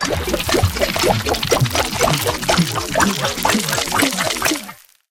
flea_egg_growing_01.ogg